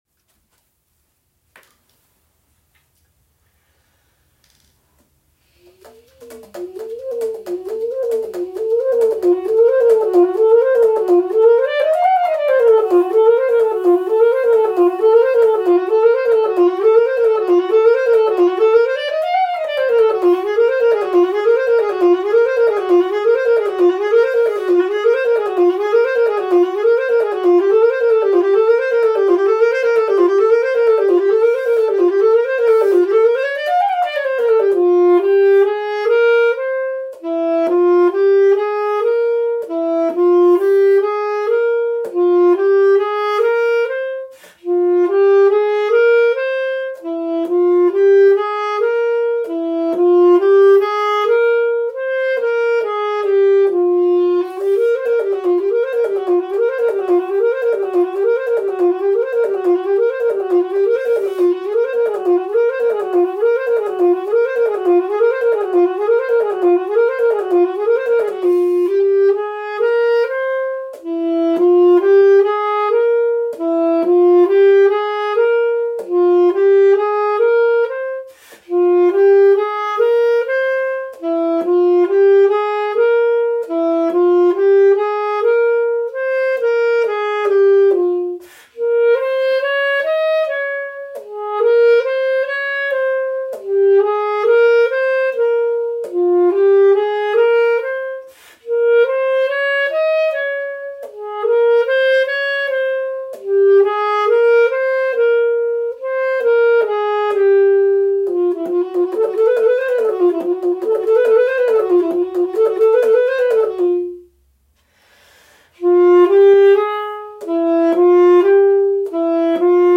"L'Eternel Bercement des Houles Enivrantes" per saxofono contralto.